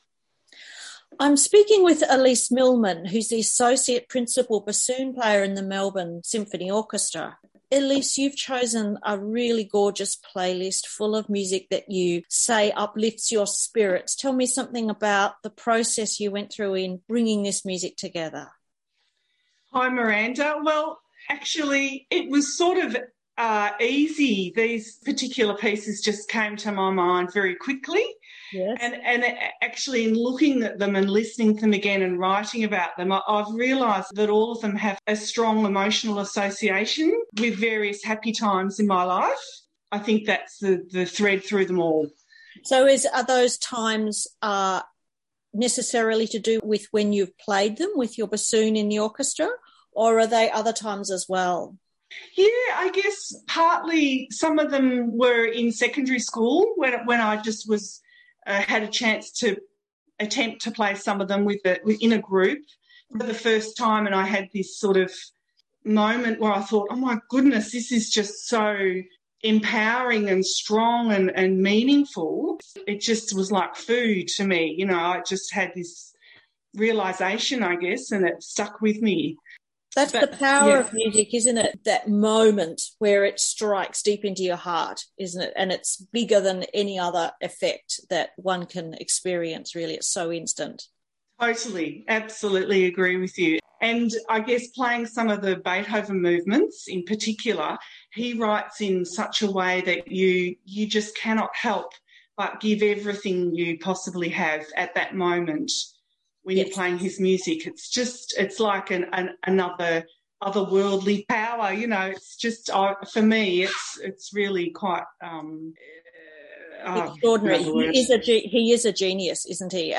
Each musician has provided a short introduction to their playlist exploring the meaning the music has for them personally.